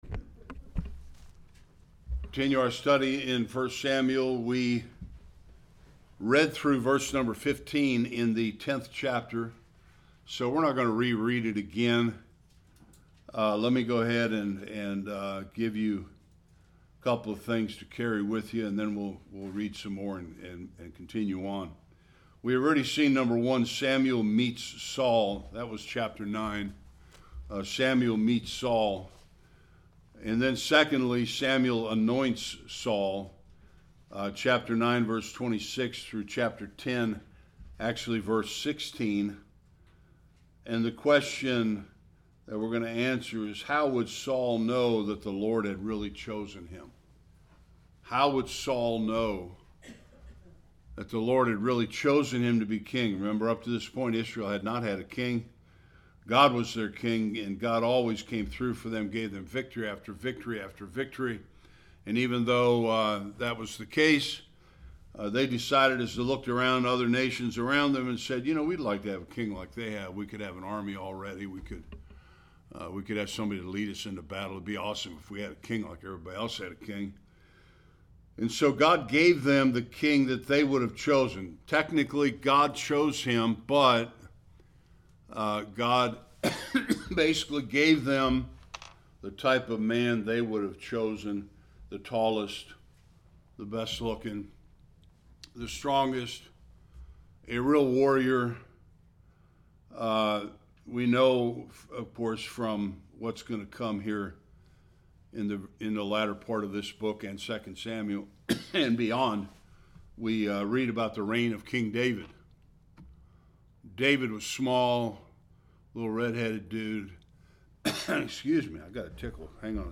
1-27 Service Type: Sunday School Saul wins a major military victory for Israel as their new king.